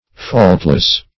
Faultless \Fault"less\, a.